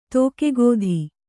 ♪ tōke gōdhi